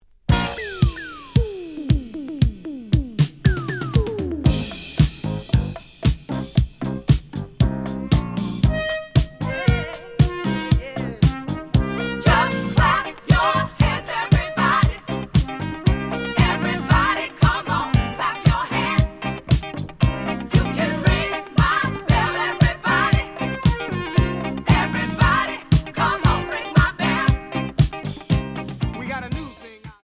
(Mono)
試聴は実際のレコードから録音しています。
●Genre: Disco
●Record Grading: VG+~EX- (プロモ盤。)